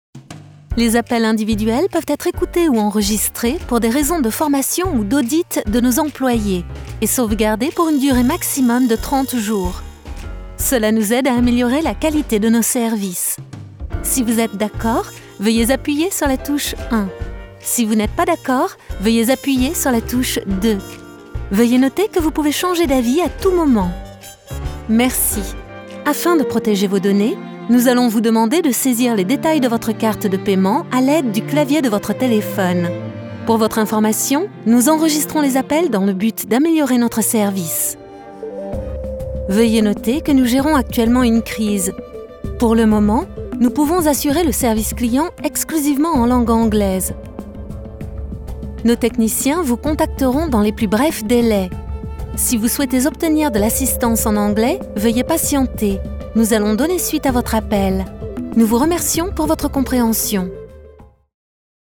Natural, Versátil, Amable, Cálida, Empresarial
Telefonía